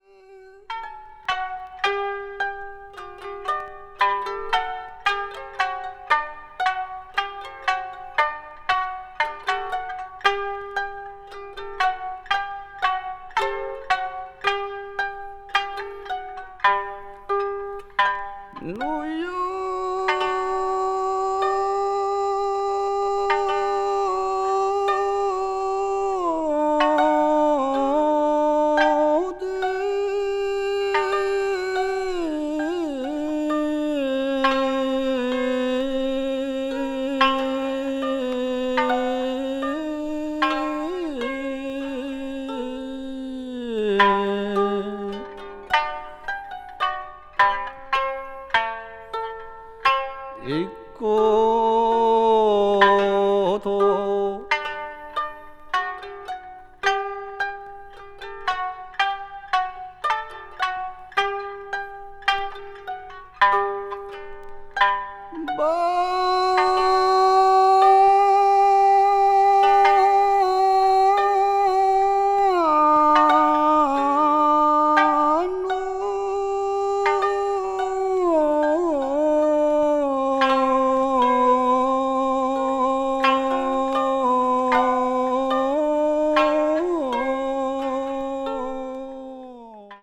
とくに「仲風」の弦の響きはとても新鮮に耳に届きます。録音も非常に秀逸。
ethnic music   japan   minyo   okinawa   ryukyu   traditional